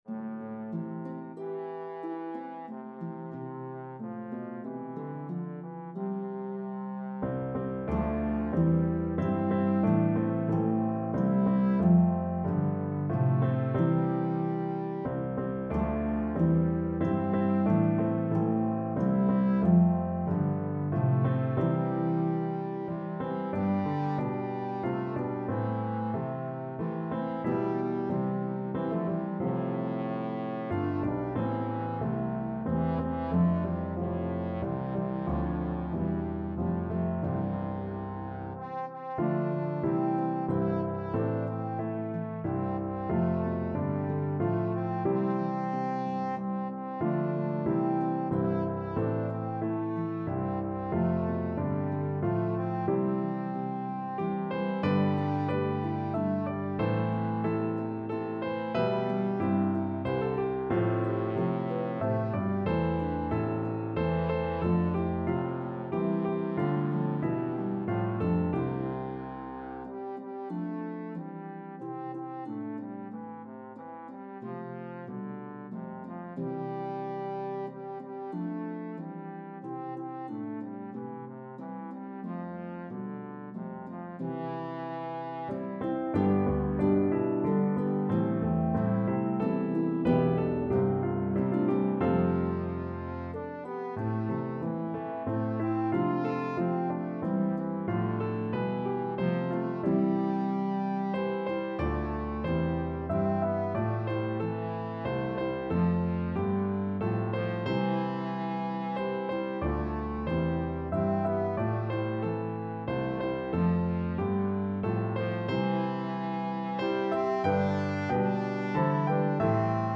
A meditative trio arrangement of the 1844 hymn tune
is a pentatonic hymn tune